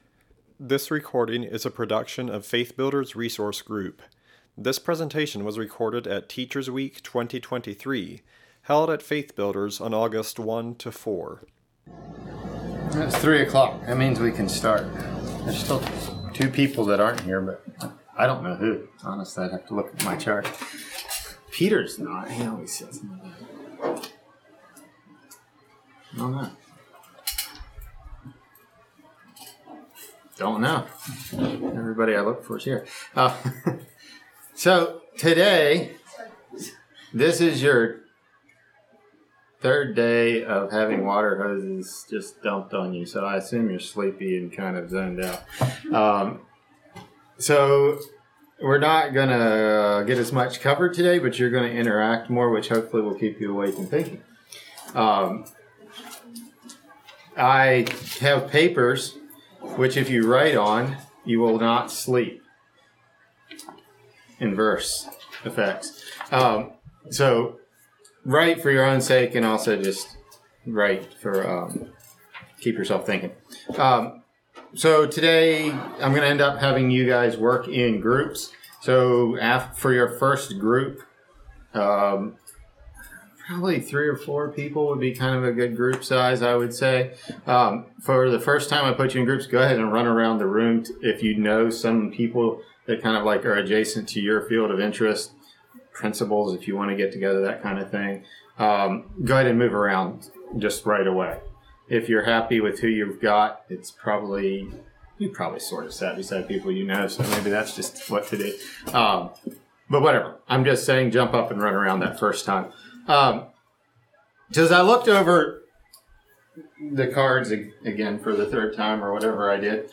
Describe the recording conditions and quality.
In this recording from Teachers Week 2023, attendees of the three-part workshop, Using your Resources to Address Behavior Issues, listed behavioral challenges they have faced or expect to face. Using these examples, they discussed resources available to help a newer teacher address these issues.